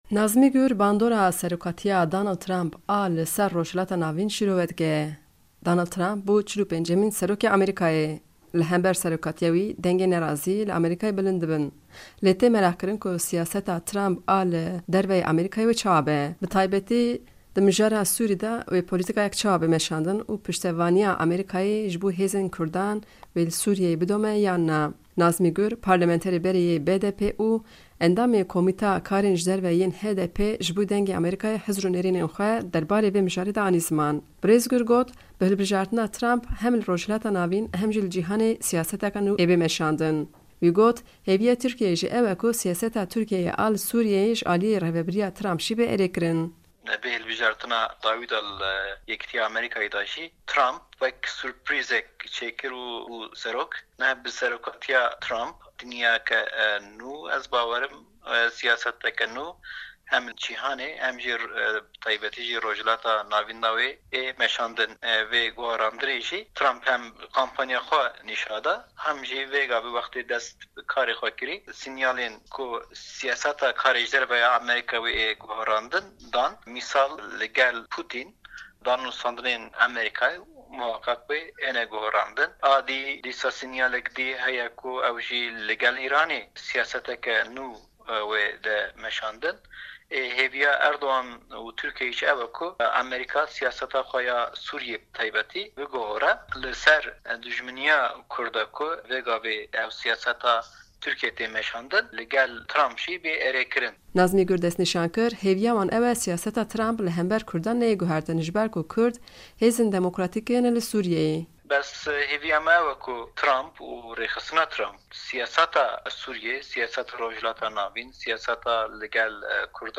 Nazmî Gur, parlamenterê berê yê BDPê û endamê komita karên derve yên HDPê jibo Dengê Amerîka çavderiyên xwe parvekirin.